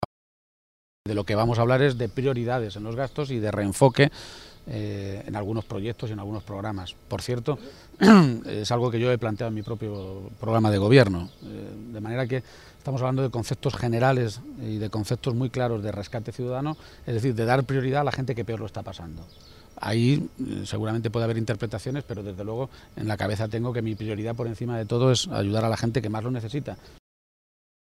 García-Page se pronunciaba de esta manera esta mañana, en Toledo, en una comparecencia ante los medios de comunicación minutos antes de que comenzara esa conversación con responsables de IU a nivel regional, en las Cortes de Castilla-La Mancha.